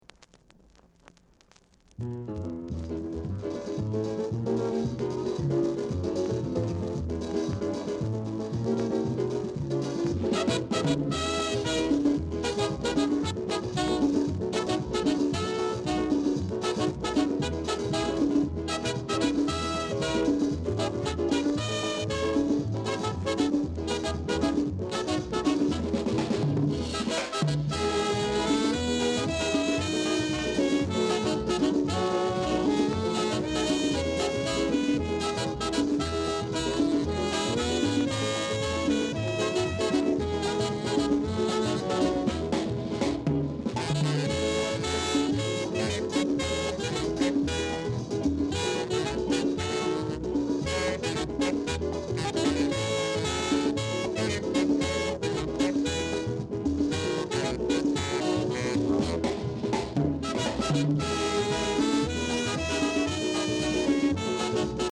Calypso Inst